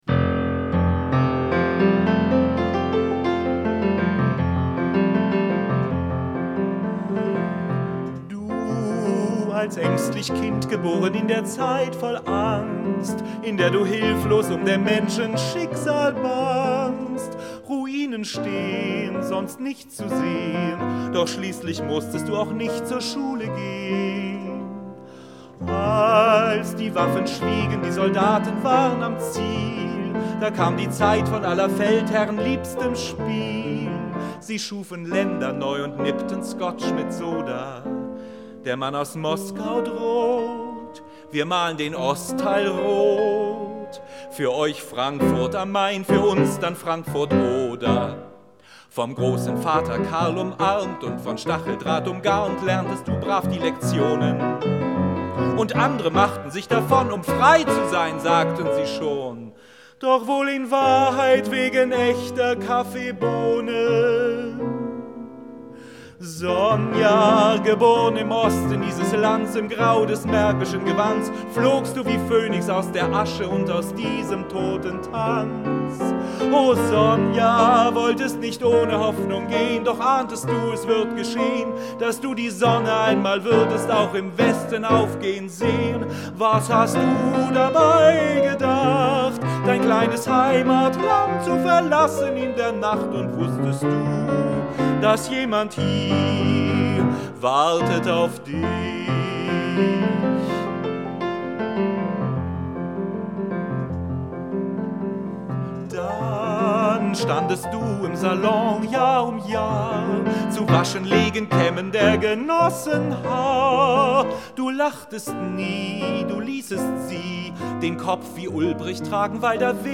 „Die Sage von Sonja“ – ein Chanson
Die Aufnahme entstand am 3. April 1993 in Hamburg.*